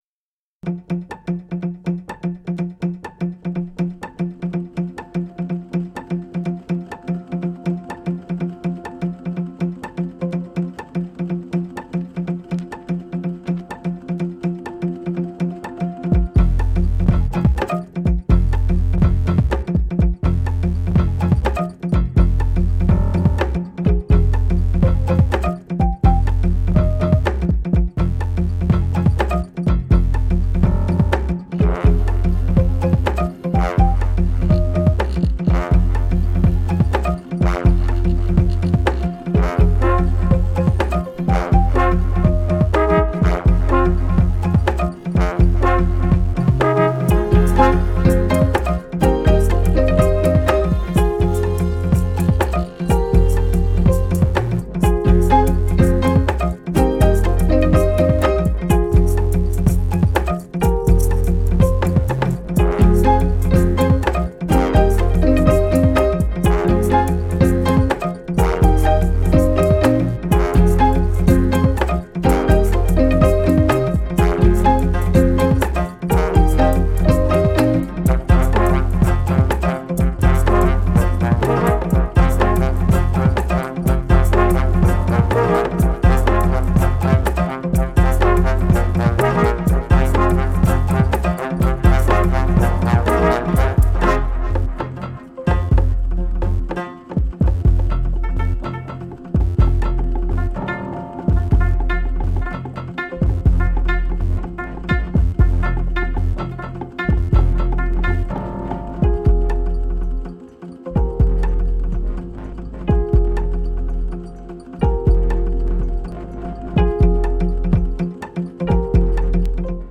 ミニマル特有のトランス感を持った、気高くも風変りなダンス・ミュージック！